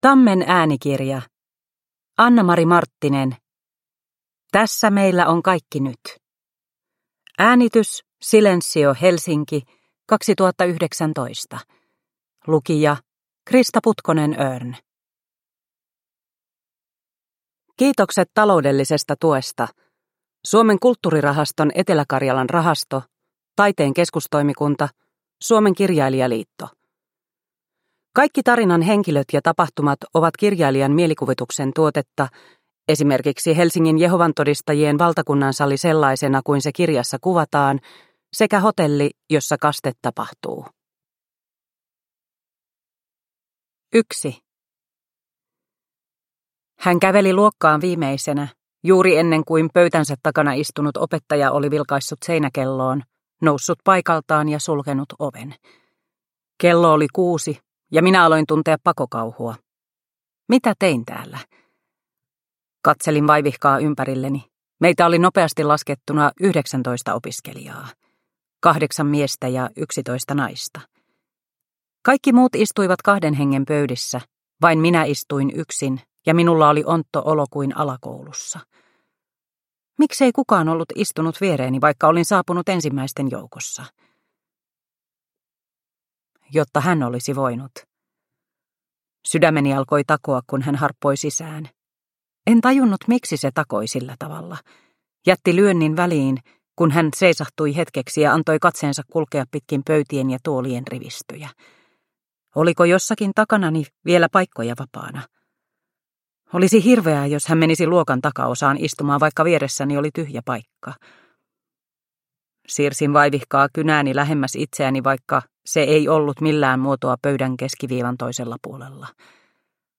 Tässä meillä on kaikki nyt (ljudbok) av Annamari Marttinen